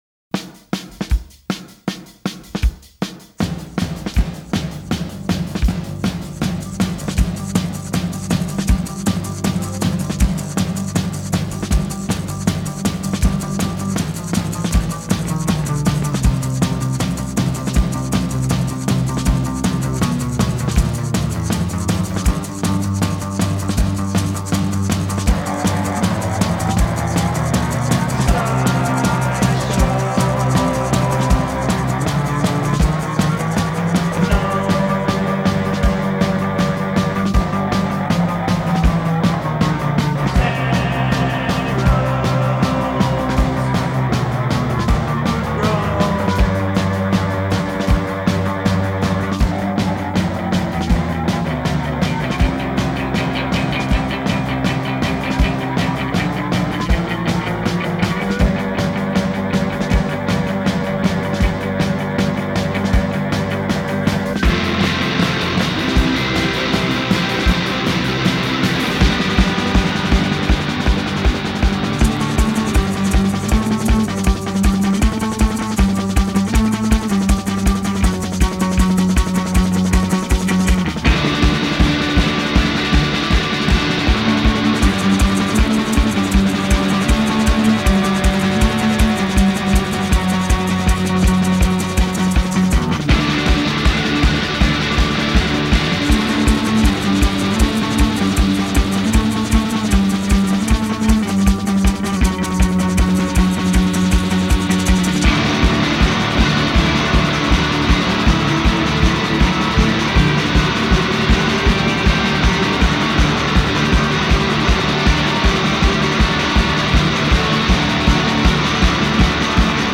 ” which is kind of a melodic wall of noise, in the best way.